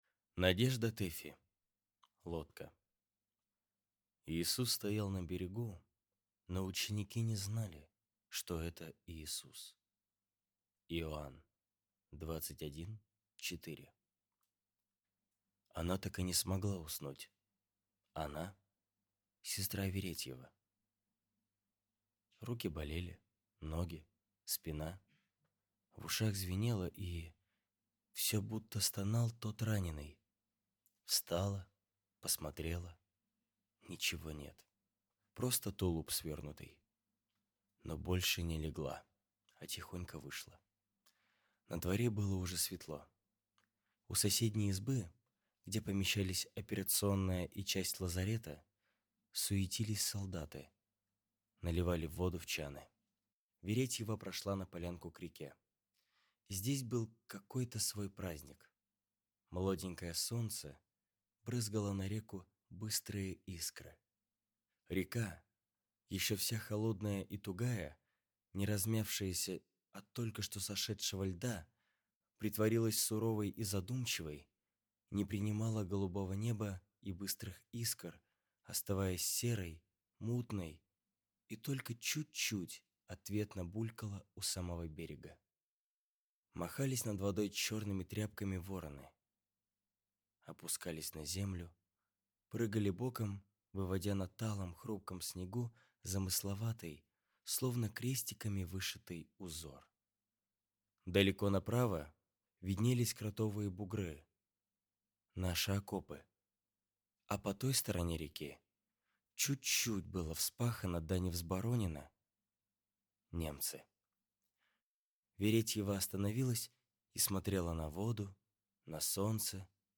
Аудиокнига Лодка | Библиотека аудиокниг
Прослушать и бесплатно скачать фрагмент аудиокниги